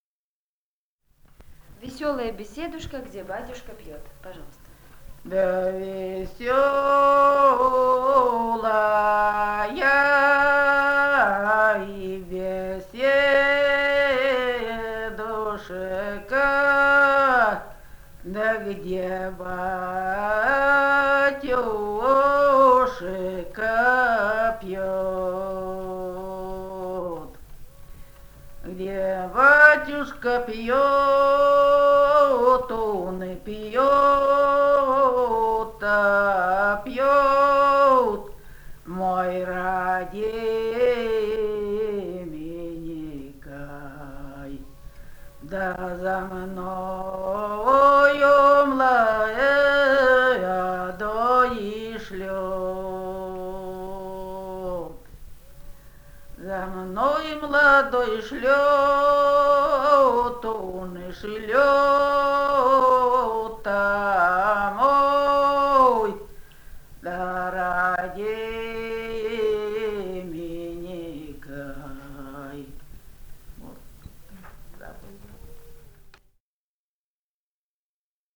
«Да весёлая беседушка» (лирическая).
Алтайский край, с. Михайловка Усть-Калманского района, 1967 г. И1001-03